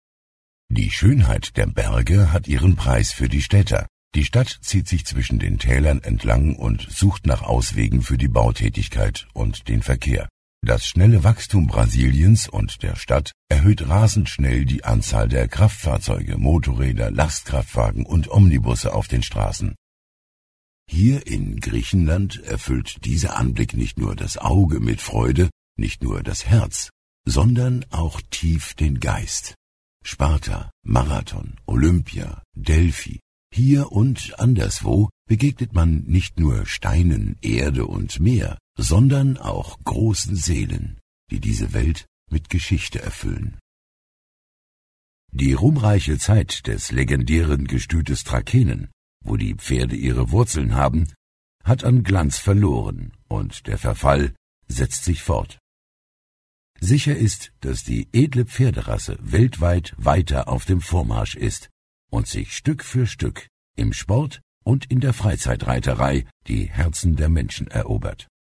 Profisprecher deutsch. Angenehme tiefe Stimme, weiche dunkle Stimme, Seniorstimme, young senior
Sprechprobe: Werbung (Muttersprache):